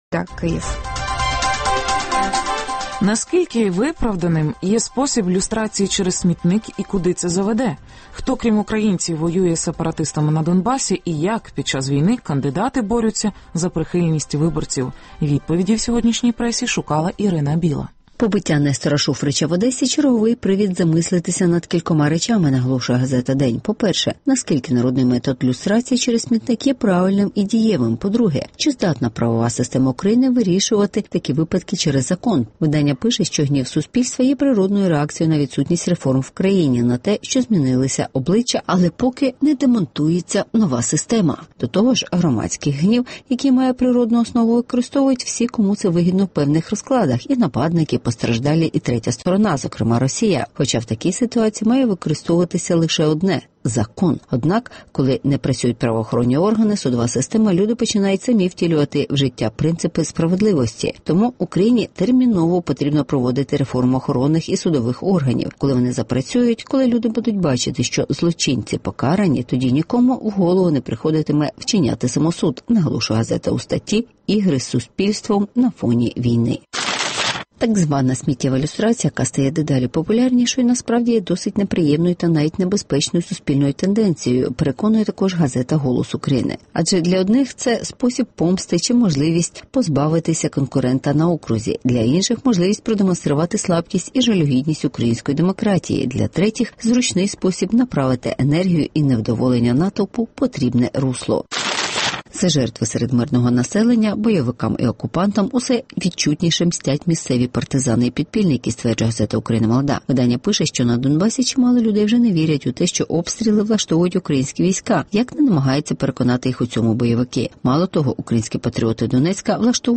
Українські месники діють на Донбасі (огляд преси)